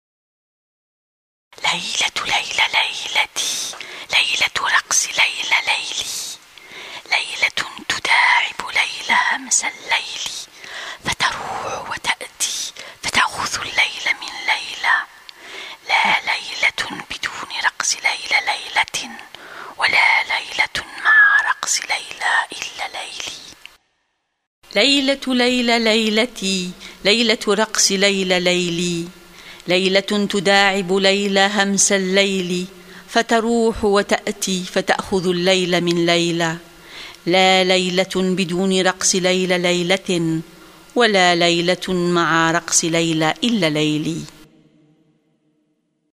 Poem’s pronunciation